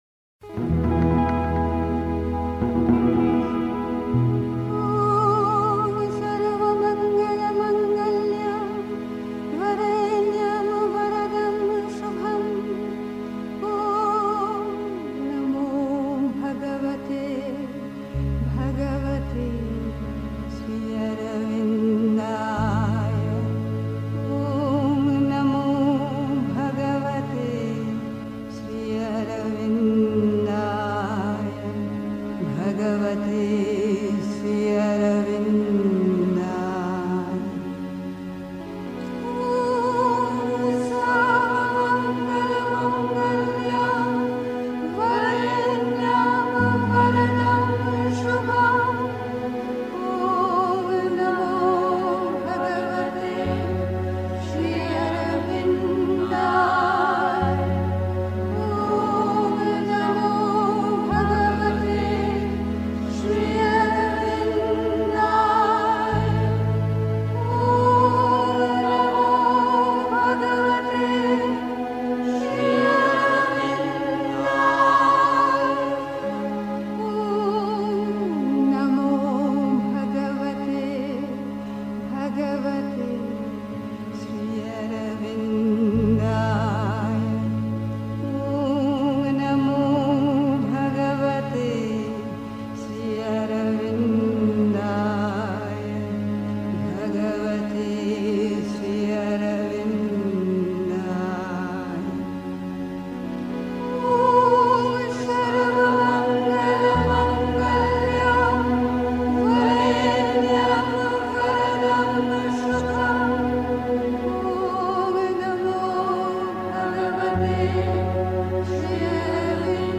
Pondicherry. 2. Als ich noch in der Unwissenheit schlief (Sri Aurobindo, Thoughts & Aphorisms) 3. Zwölf Minuten Stille.